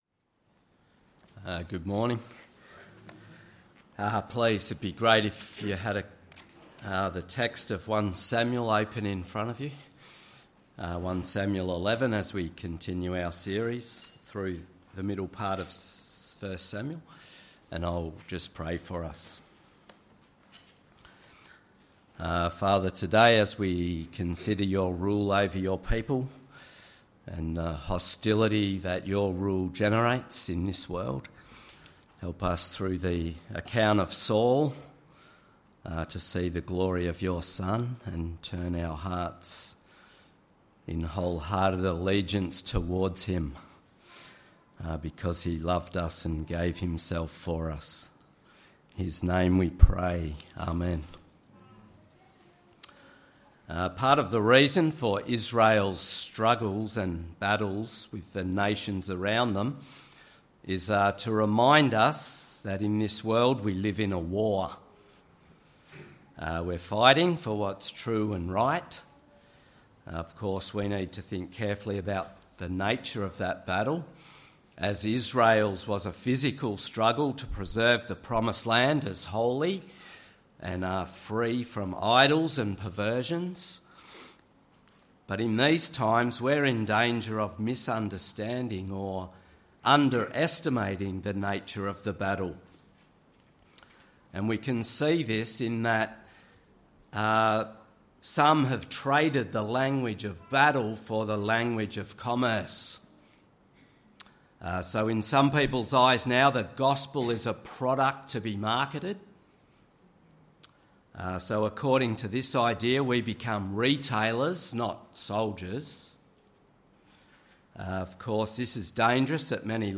Sermon: 1 Samuel 11:1-15